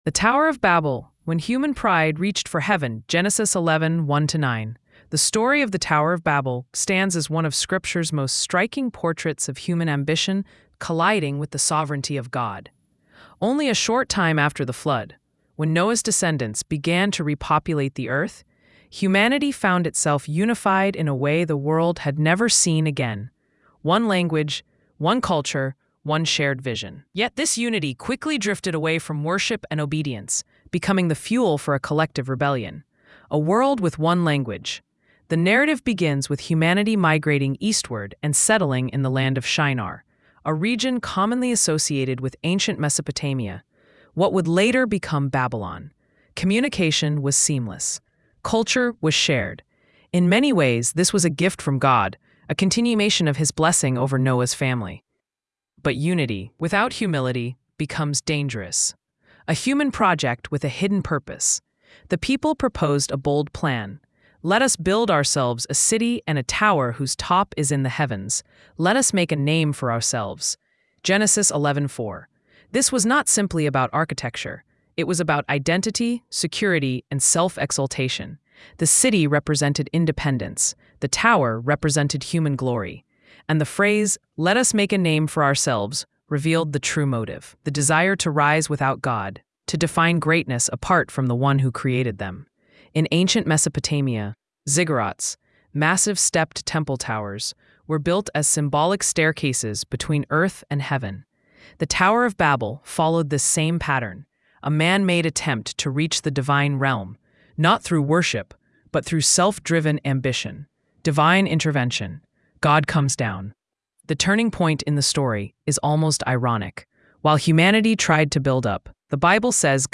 “Every Tongue” is a cinematic worship journey that moves from the scattering at Babel to the unity restored at Pentecost. The song begins with humanity divided by pride and confused languages, then rises into a powerful chorus celebrating how the Holy Spirit gathers all nations under one Name—Jesus Christ.
With emotional strings, soaring vocals, and a triumphant worship tone, “Every Tongue” declares that every language, every people, and every voice was made to proclaim His glory.